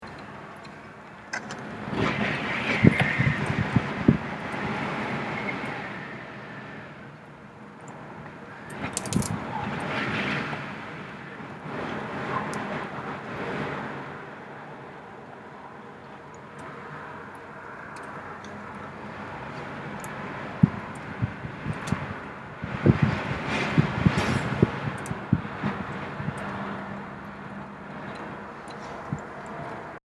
Field Recording #2 – Wind and Rain
This field recording is taken inside our greenhouse; it’s basically a screened in porch, the windows don’t fully close so, although it’s freezing, it’s really awesome for listening for new sounds. I captured the sound of the wind and rain dripping off the roof of the greenhouse.
It’s a quiet night and the rain is very soothing.
DRAM-020-1-Wind.mp3